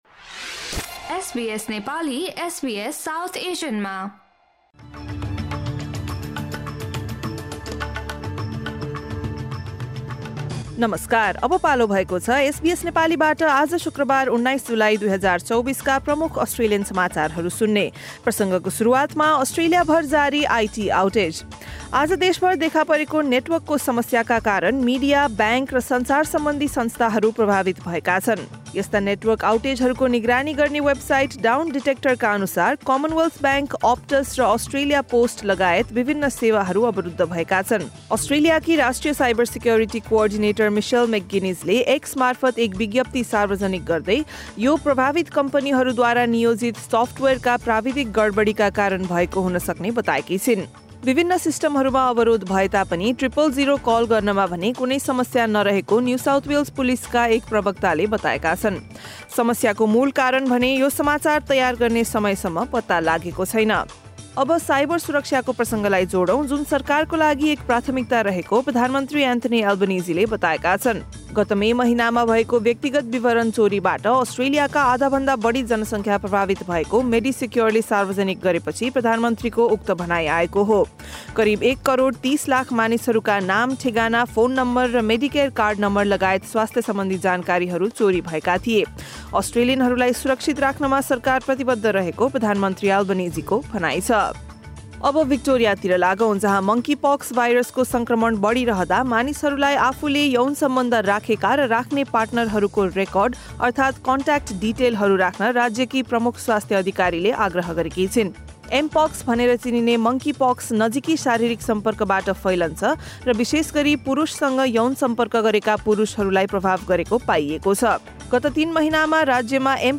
SBS Nepali Australian News Headlines: Friday, 19 July 2024